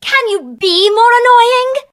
bea_hurt_vo_03.ogg